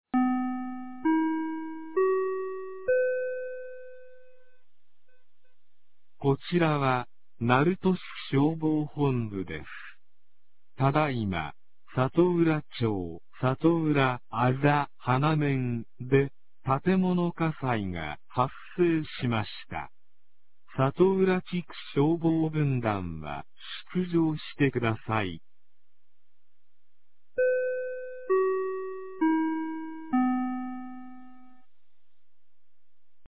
2025年11月16日 01時42分に、鳴門市より里浦町-里浦へ放送がありました。